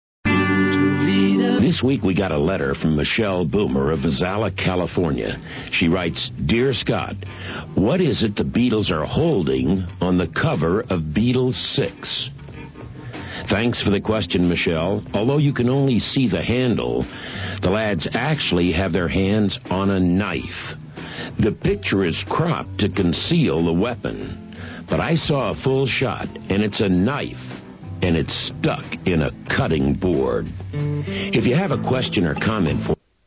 Scott Muni's Ticket To Ride radio show: